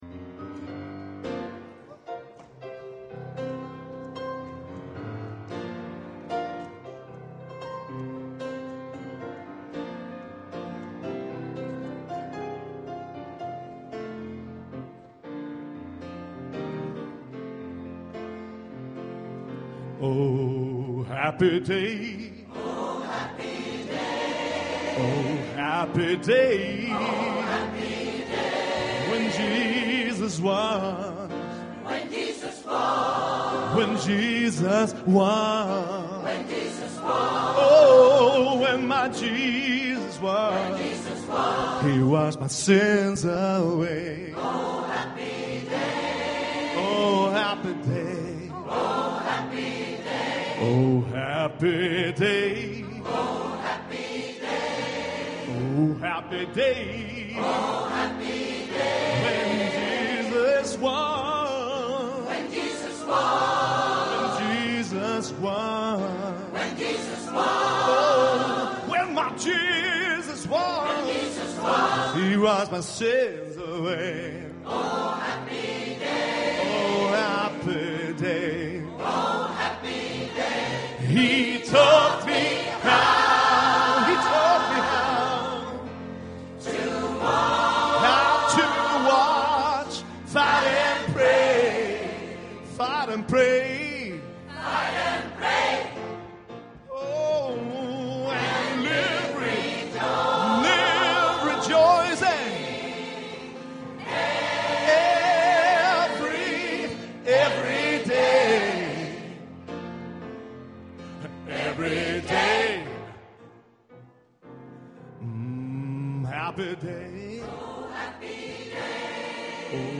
Inspelad i Tabernaklet i Göteborg 2017-04-09.